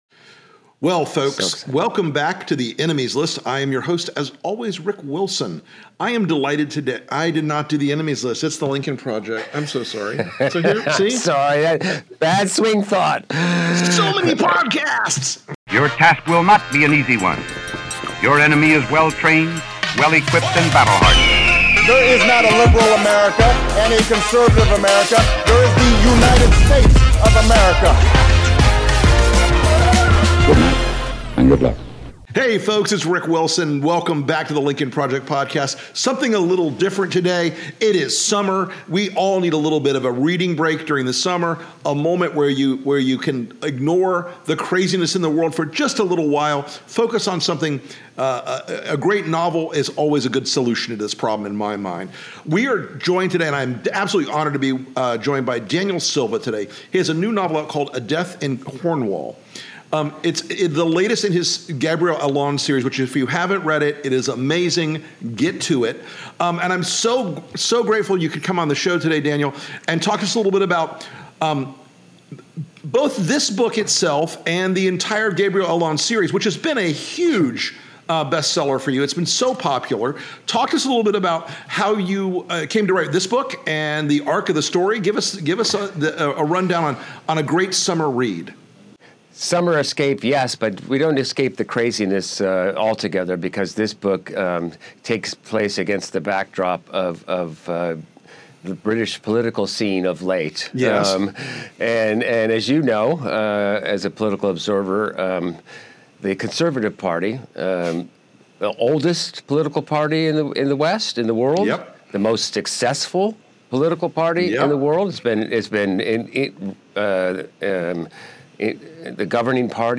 Rick Wilson talks with New York Times bestselling author Daniel Silva about his latest novel, 'A Death in Cornwall,' which takes place against the backdrop of the British political scene. The book explores the use of fine art for money laundering and the global oligarchy of the super-rich. The conversation also touches on the rise of Putinism, the link between doctrinaire Christianity and hard-right authoritarian politics, and the historical context of his Gabriel Allon series.